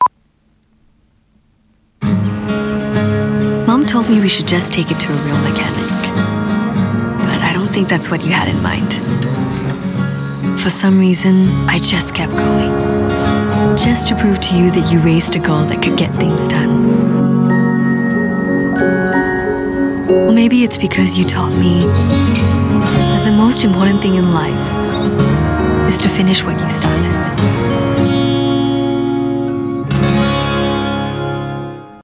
Voice Sample: Tokio Marine
EN Asian EN SG
We use Neumann microphones, Apogee preamps and ProTools HD digital audio workstations for a warm, clean signal path.